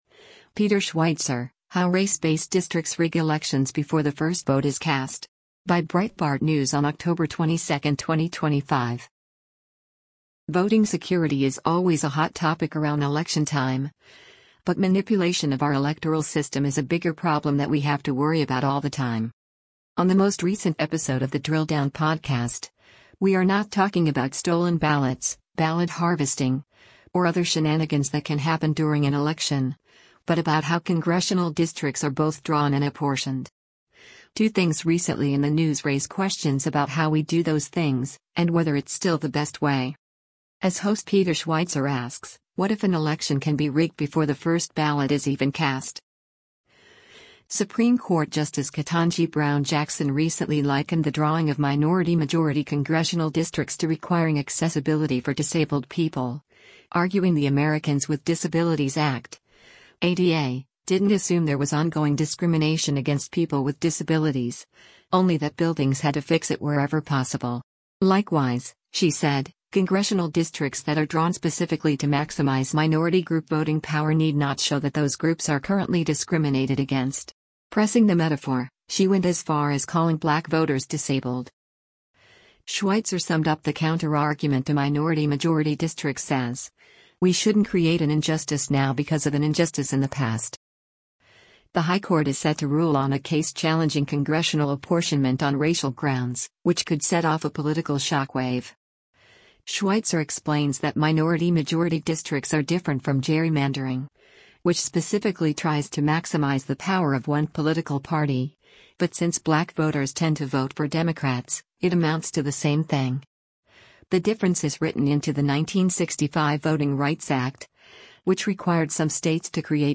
As host Peter Schweizer asks, “What if an election can be rigged before the first ballot is even cast?”